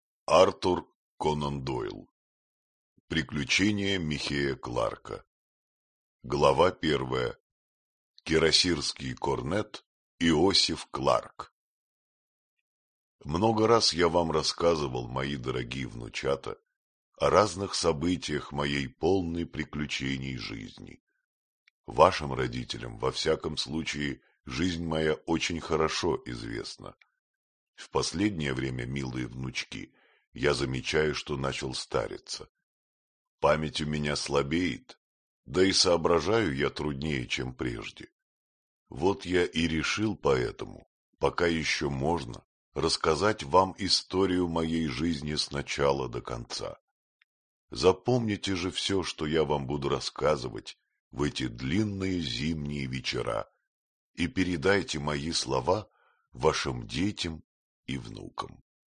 Аудиокнига Приключения Михея Кларка | Библиотека аудиокниг